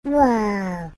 Sound Effects Soundboard0 views